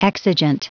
Prononciation du mot exigent en anglais (fichier audio)
Prononciation du mot : exigent